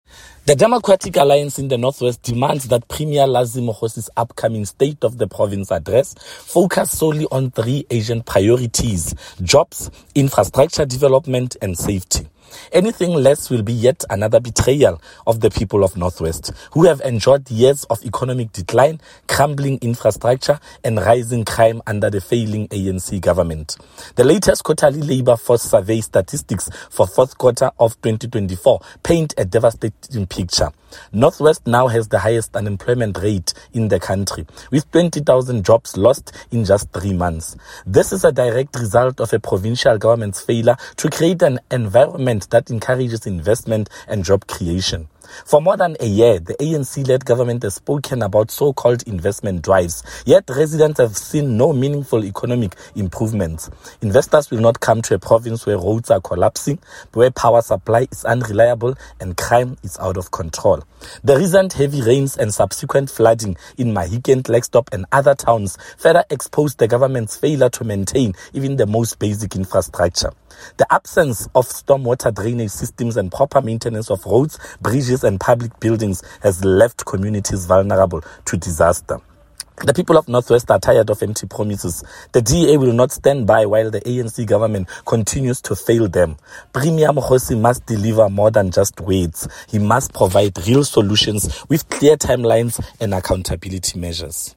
Issued by Freddy Sonakile – DA Caucus Leader in the North West Provincial Legislature
Note to Broadcasters: Please find attached soundbite in
English by Freddy Sonakile